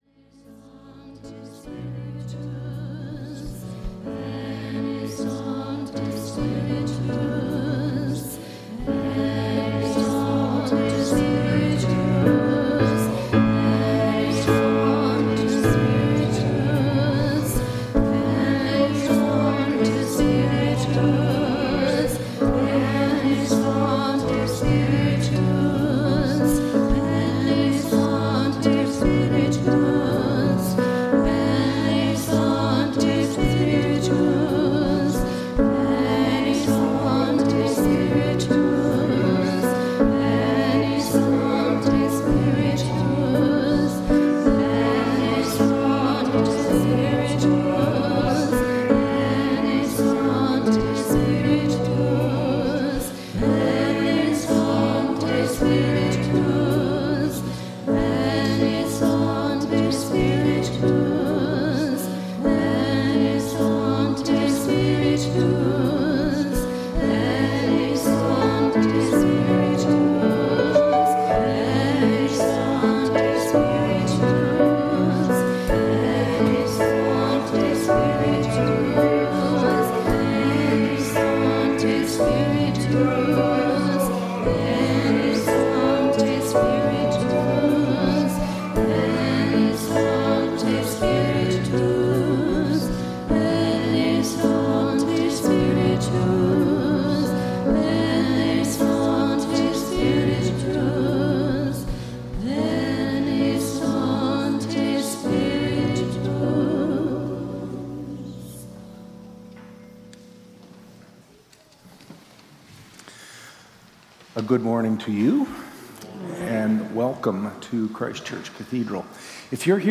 Worship and Sermon audio podcasts
WORSHIP - 10:30 a.m. Day of Pentecost